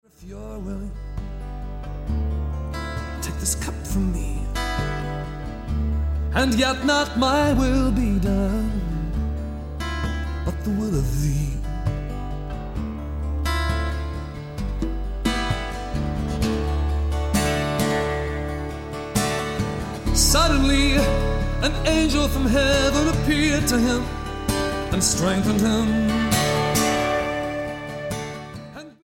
STYLE: Pop
acoustic guitar